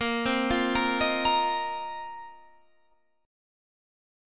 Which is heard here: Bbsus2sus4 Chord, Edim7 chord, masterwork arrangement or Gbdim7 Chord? Bbsus2sus4 Chord